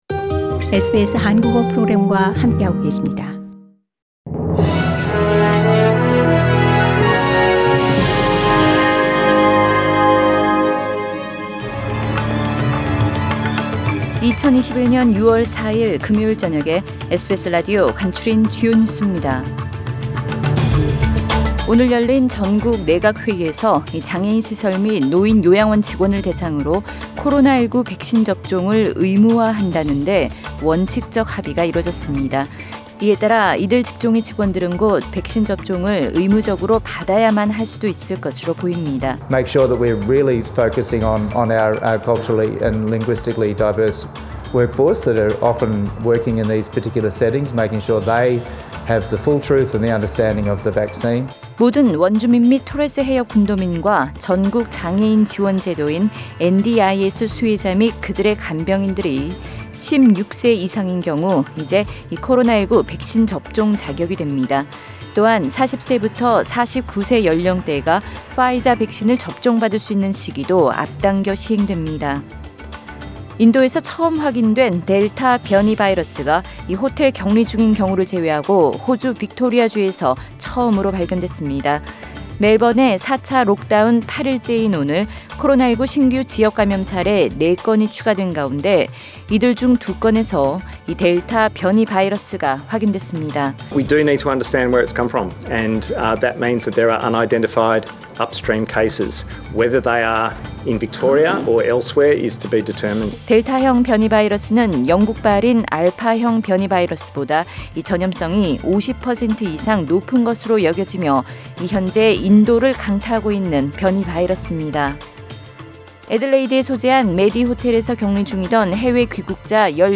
SBS News Outlines…2021년 6월 4일 저녁 주요 뉴스
2021년 6월 4일 금요일 저녁의 SBS 뉴스 아우트라인입니다.